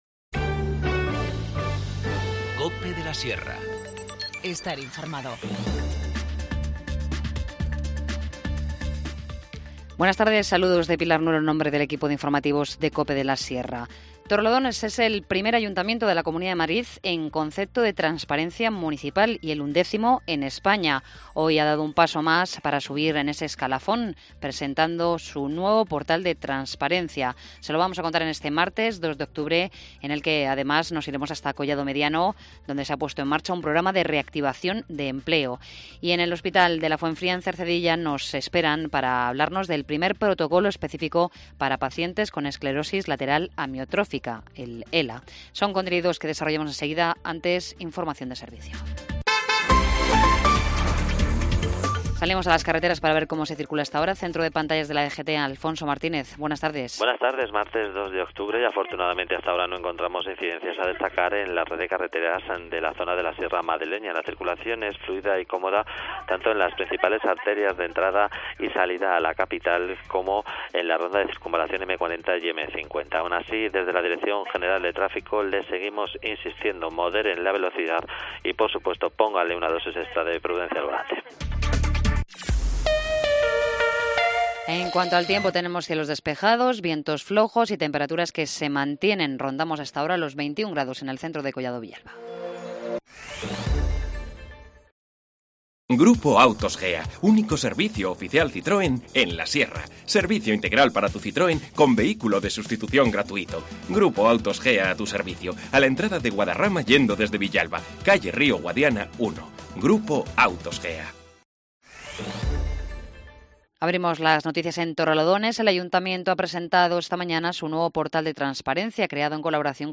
INFORMATIVO MEDIODÍA 2 OCT- 14:20h